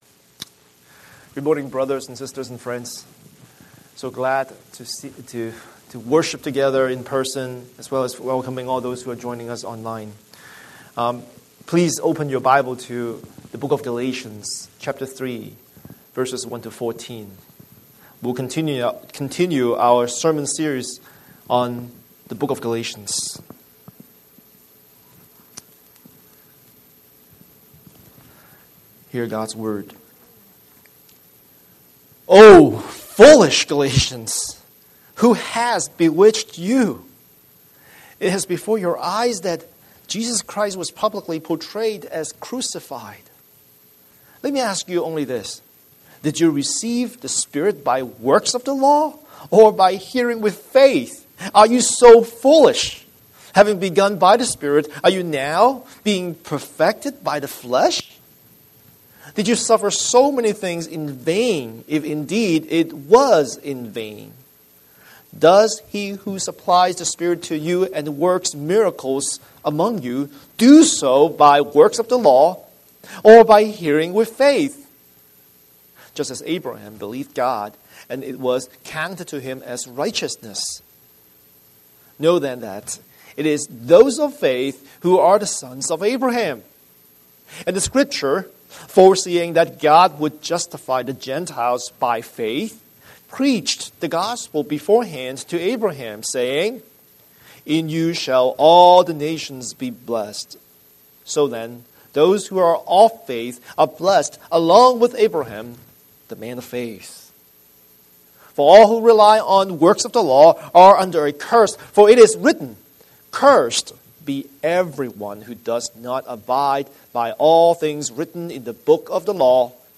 Scripture: Galatians 3:1–14 Series: Sunday Sermon